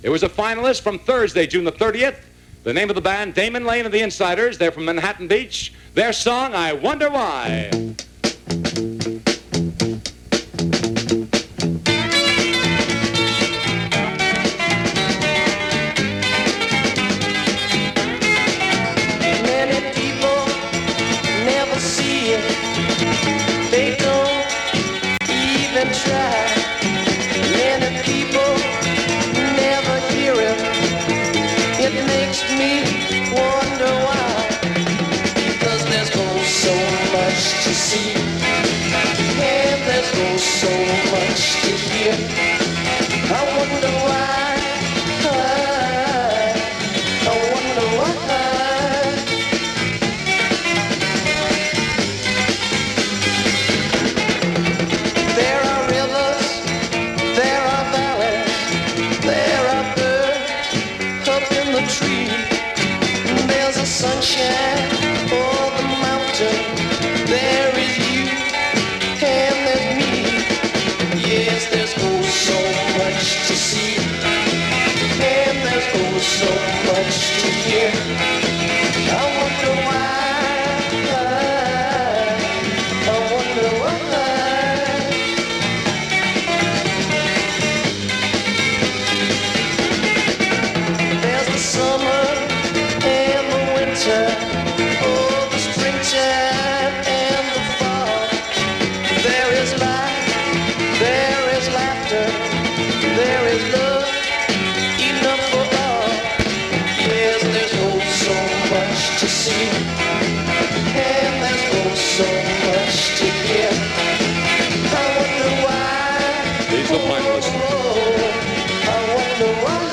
KFWB-Los Angeles – B. Mitchel Reed – Battle Of The Bands Semi-Finals – July 1966 –
KFWB-July-1966-BMR-Battle-Of-the-Bands.mp3